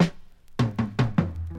Chopped Fill 5.wav